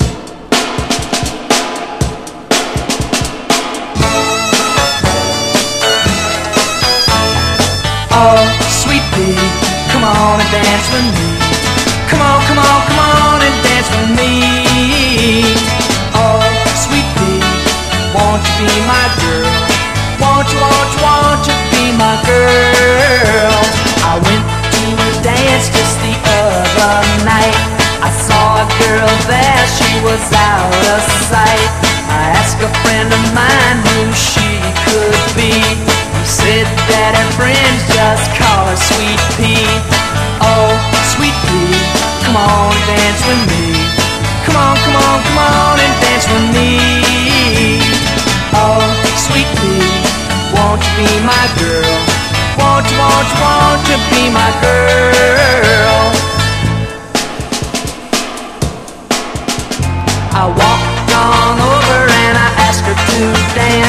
¥3,780 (税込) ROCK / SOFTROCK.